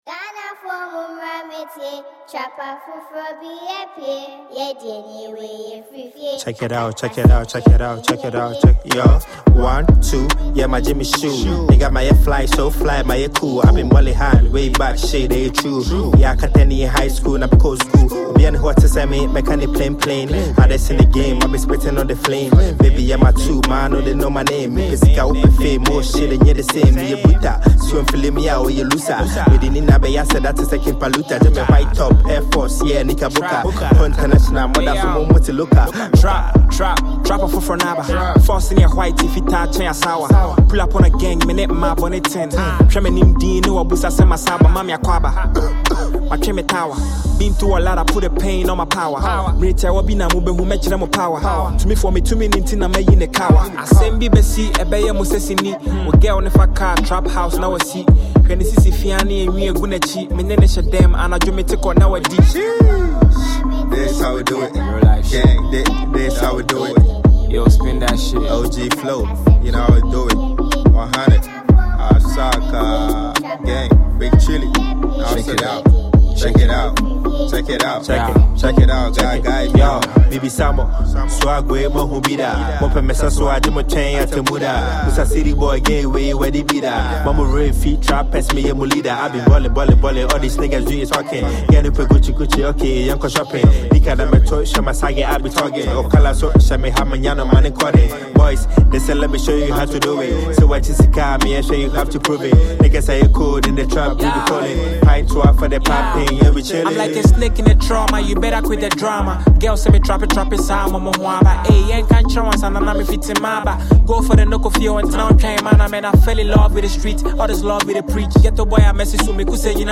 a powerful anthem that blends grit, rhythm, and confidence
His sound is bold, authentic, and built for impact.